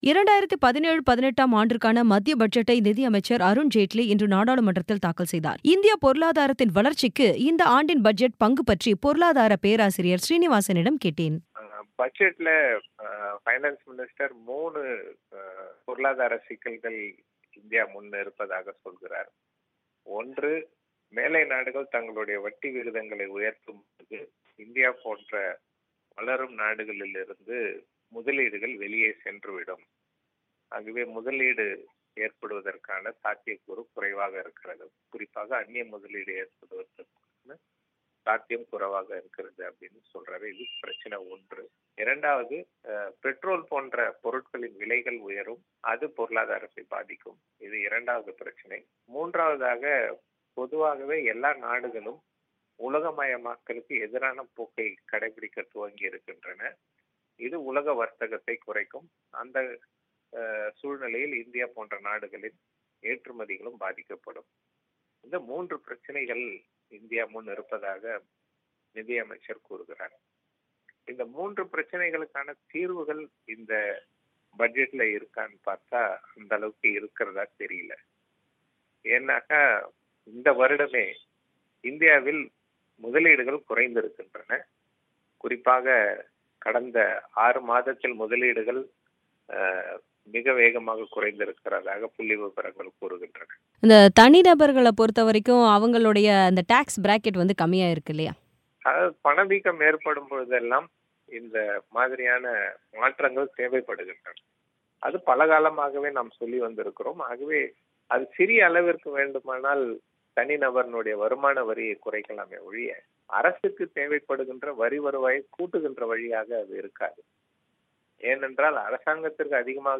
பேட்டி